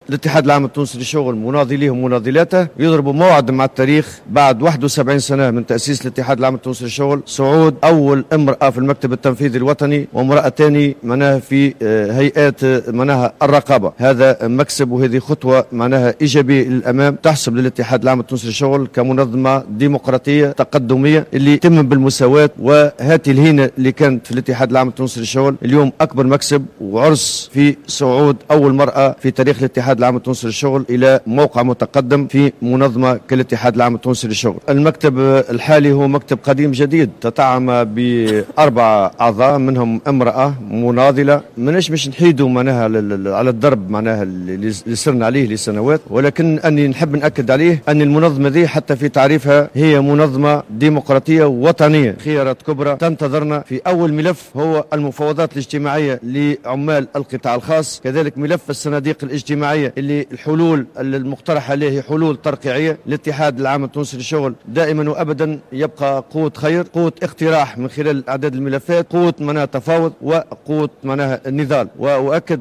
قال الامين العام الجديد للاتحاد العام التونسي للشغل، نور الدين الطبوبي، في أول تصريح إعلامي له بعد توليه مهامه الجديدة على رأس المنظمة الشغيلة، أن ملفات المفاوضات الاجتماعية في القطاع الخاص، والأزمة التي تمر بها الصناديق الاجتماعية، بالإضافة إلى السياسات العامة في الصحة والتربية، ستكون ضمن أولويات عمل المكتب التنفيذي الجديد.